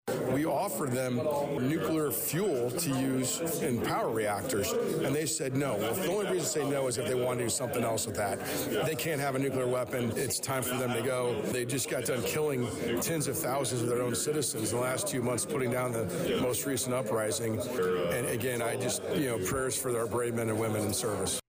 Both Schweizer and Rose were in attendance at Monday (Mar 2nd) morning’s Vermilion County Farm Bureau Legislators Meeting.